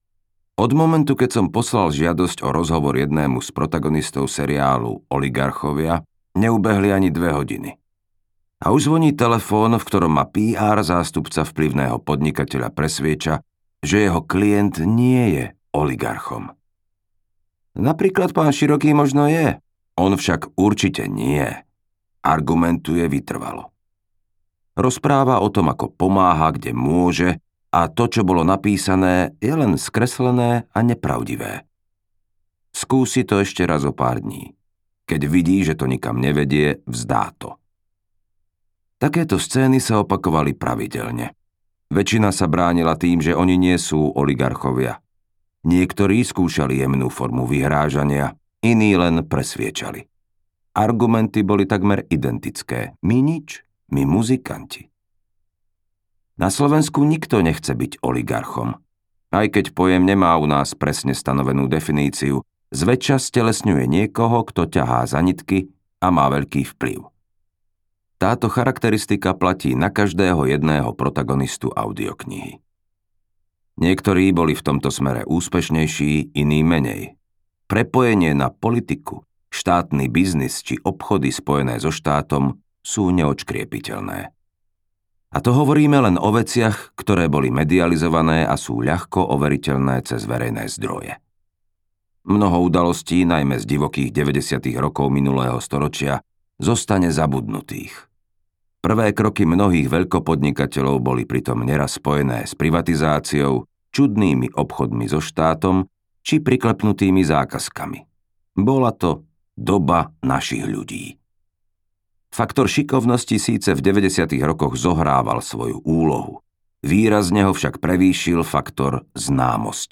Oligarchovia audiokniha
Ukázka z knihy